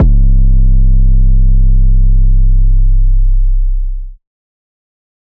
• 50 high-quality, hard-hitting 808 drum samples.
• A variety of tones from clean subs to gritty distortion.
Bombz-808-Sample-C1-6.mp3